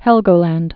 (hĕlgō-lănd, -länt)